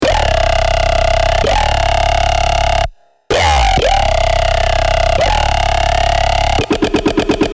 ❇ Ready for BASS HOUSE, TRAP, UKG, DRUM & BASS, DUBSTEP and MORE!
TN - WTF Tone [C] 128BPM
TN-WTF-Tone-C-128BPM.mp3